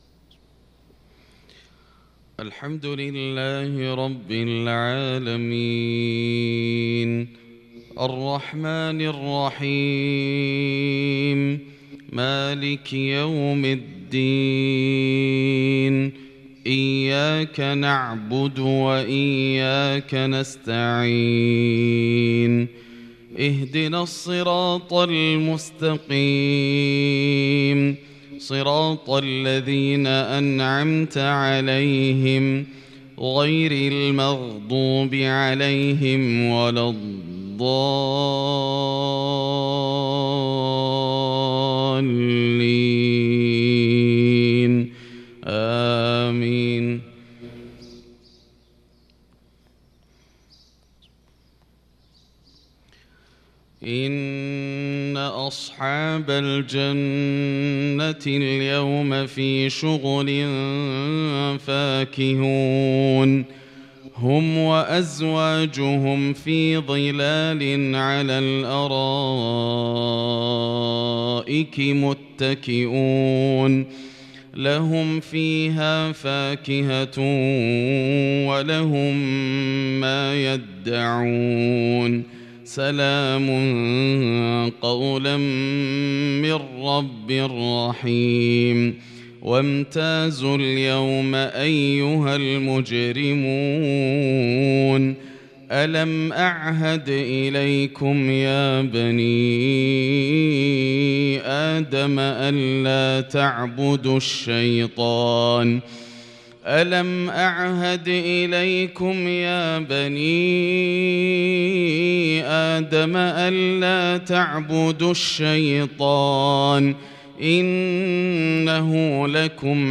صلاة الفجر للقارئ ياسر الدوسري 8 ربيع الأول 1443 هـ
تِلَاوَات الْحَرَمَيْن .